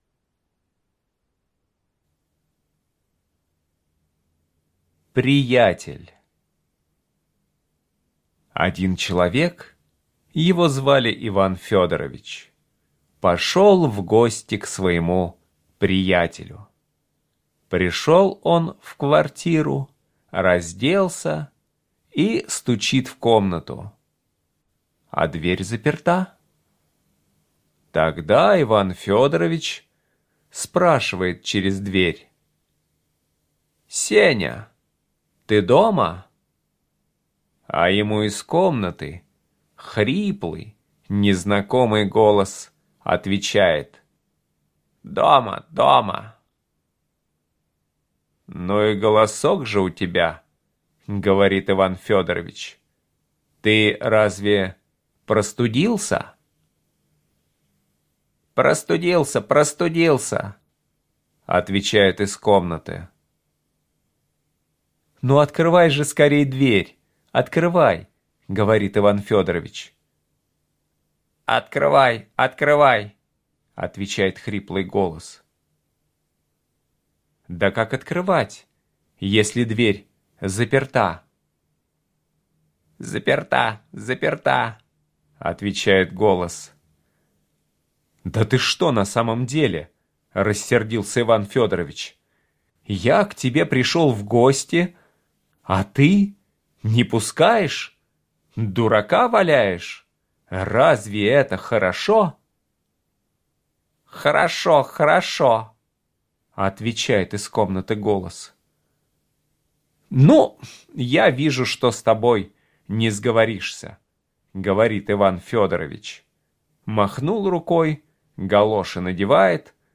Слушайте Приятель - аудио рассказ Чарушина Е.И. Как-то раз пришел автор к своему приятелю. Стучит в дверь, а тот не открывает, но повторяет его слова.